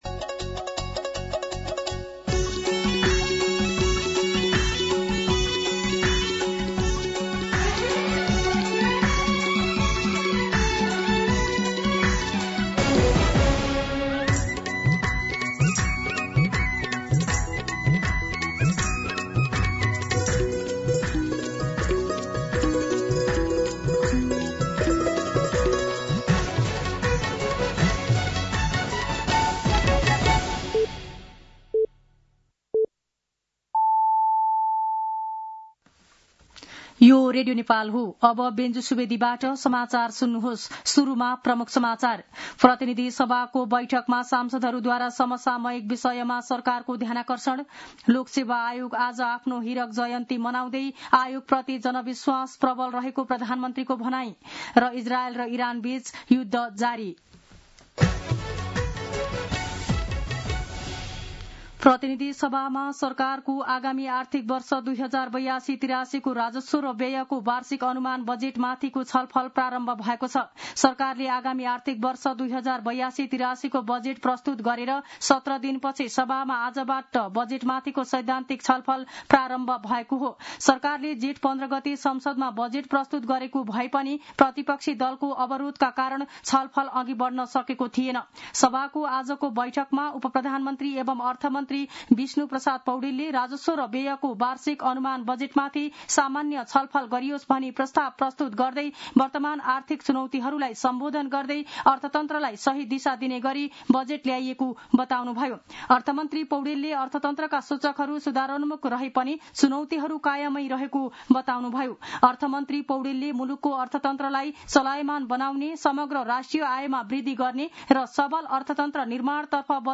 दिउँसो ३ बजेको नेपाली समाचार : १ असार , २०८२
3pm-News-03-01.mp3